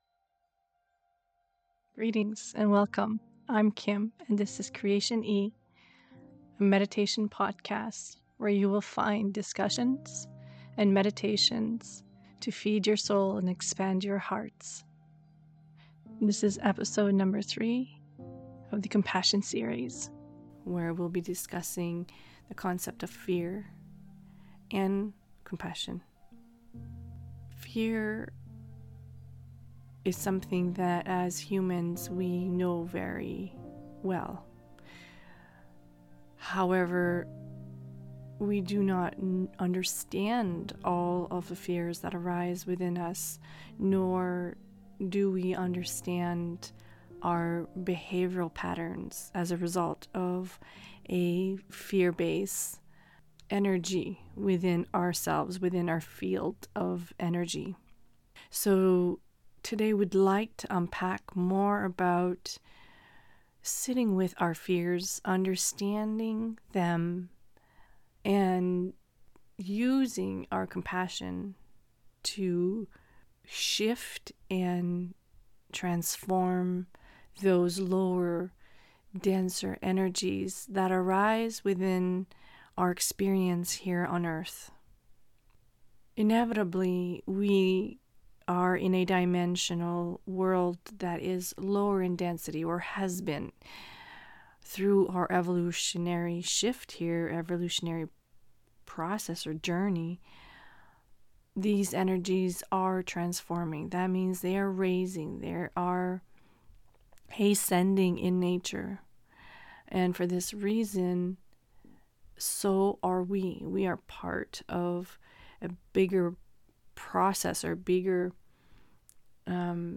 The discussion is followed by a meditation of holding space in our heart to meet the uncomfortable and shift the lower energetic patterns that arise to the surface in our every day.